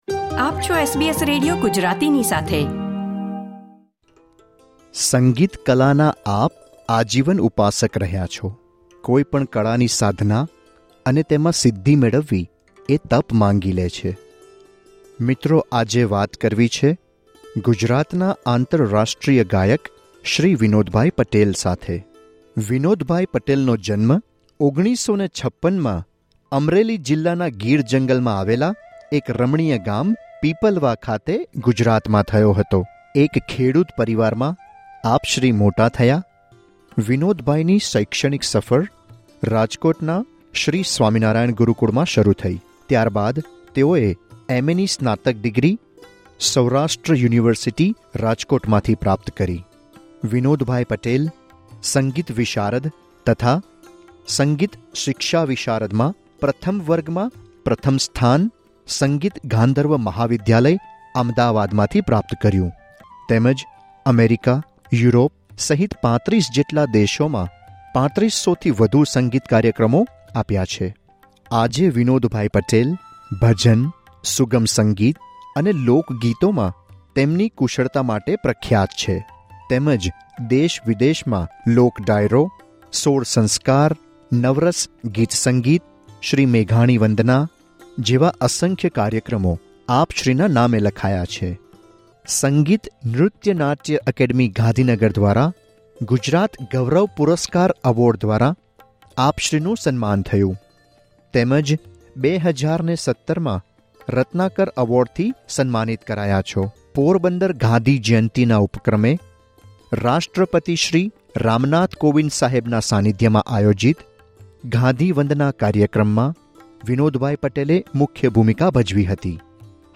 તેમની ઓસ્ટ્રેલિયા મુલાકાત દરમિયાન SBS Gujarati સાથેની મુલાકાત.